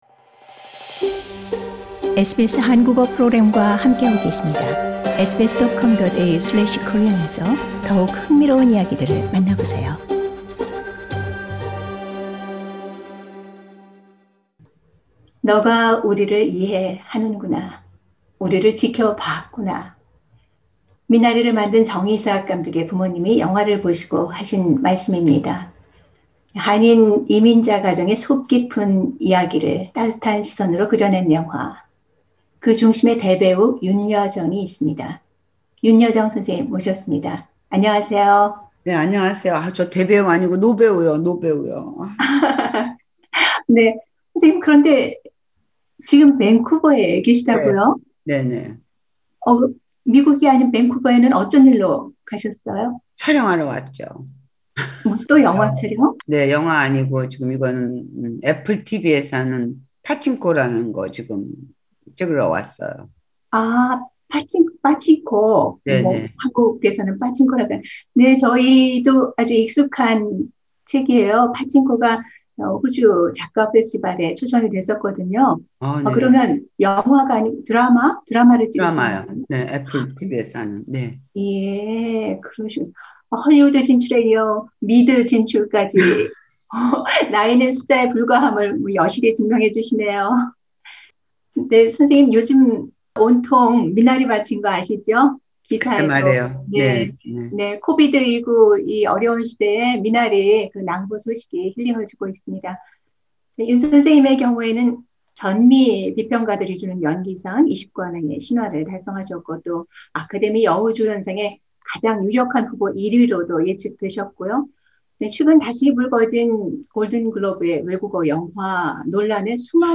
SBS 한국어 프로그램 화상 특별 대담